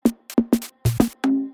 • Качество: 256, Stereo
Короткий звук на смс-сообщение.